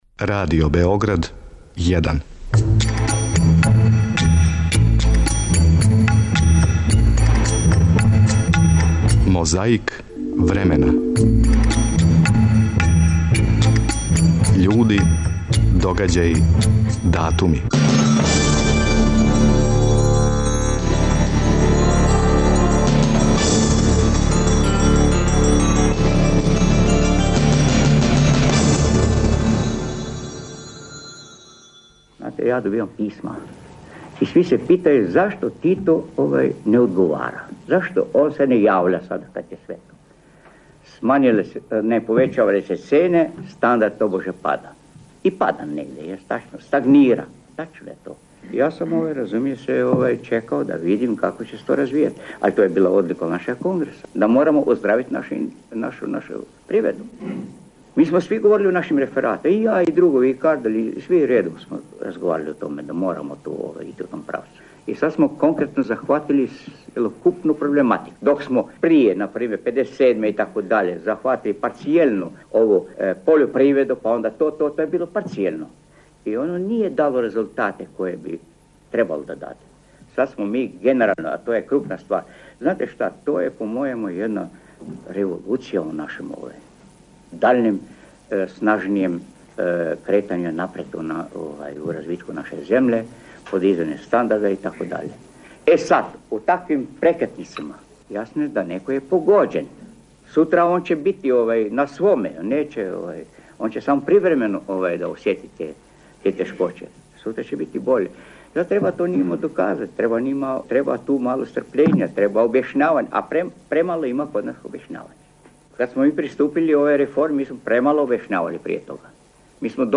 Чућемо и одломке из неких од Матавуљевих дела.
Чућемо и два прикладна говора два наша лидера са најдужим стажом у новијој историји.